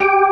FST HMND G4.wav